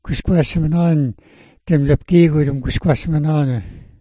gwisgwa̱sma̱n'on [gwis/*gwa̱s/m/a̱n/*'on]